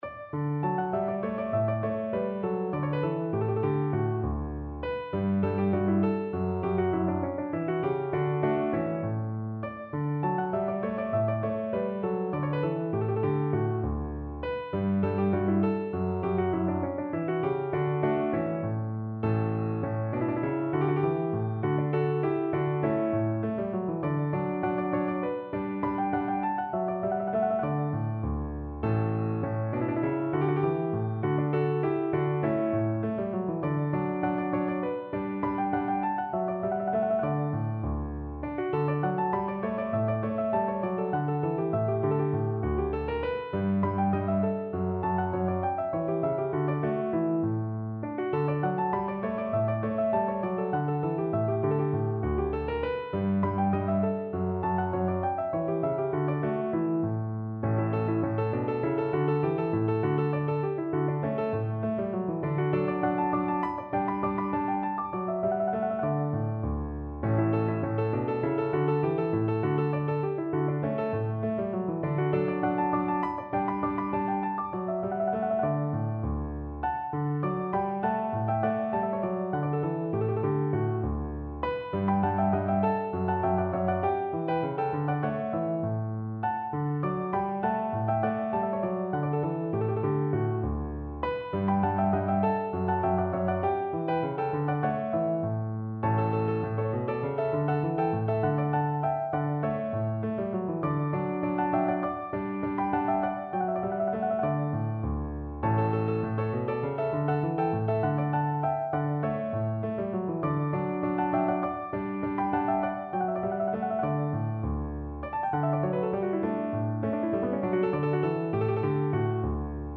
Free Sheet music for Piano
No parts available for this pieces as it is for solo piano.
D major (Sounding Pitch) (View more D major Music for Piano )
2/4 (View more 2/4 Music)
Piano  (View more Intermediate Piano Music)
Classical (View more Classical Piano Music)